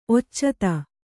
♪ occata